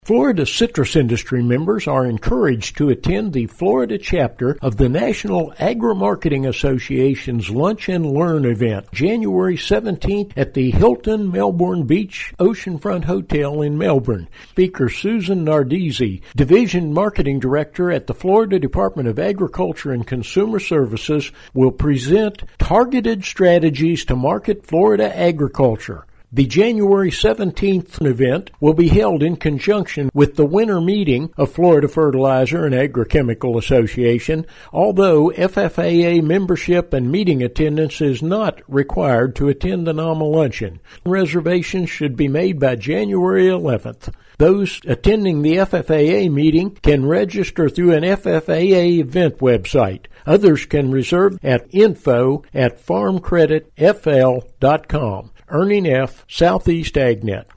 The Fresh from Florida program will be discussed. Details are in this report.